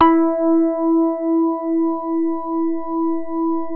JAZZ SOFT E3.wav